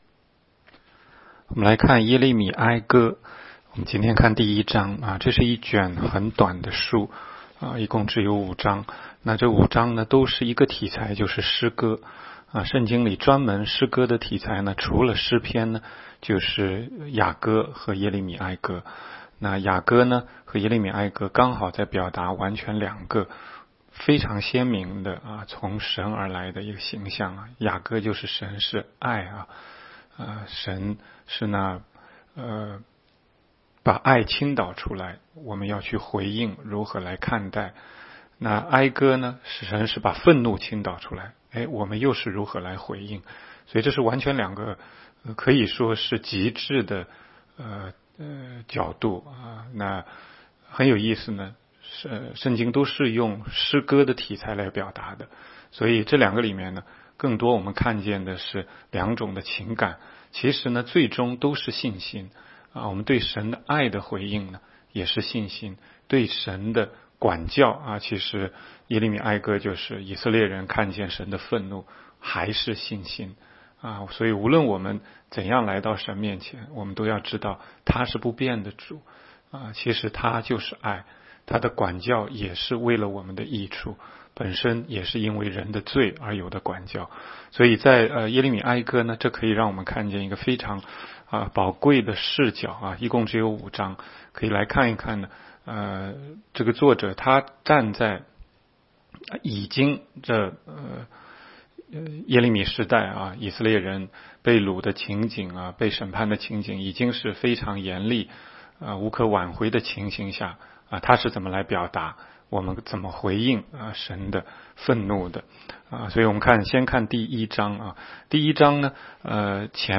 16街讲道录音 - 每日读经-《耶利米哀歌》1章
每日读经